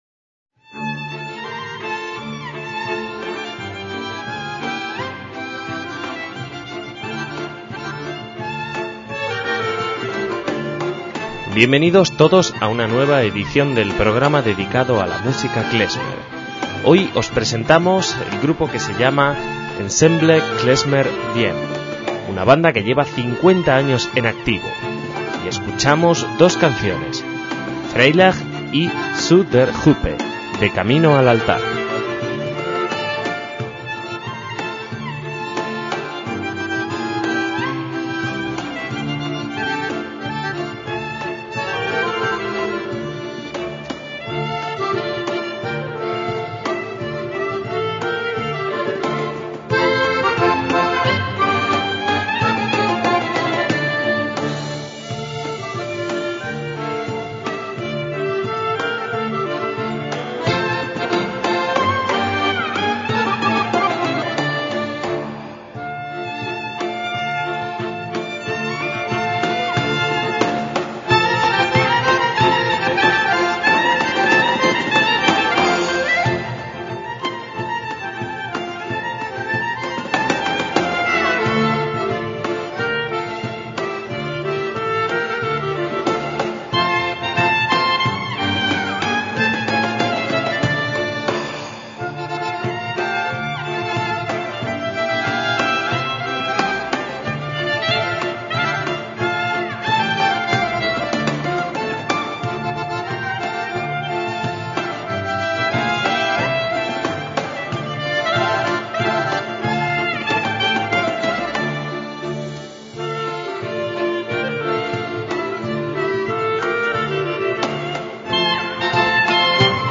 MÚSICA KLEZMER
acordeón
clarinete
viola
contrabajo
percusión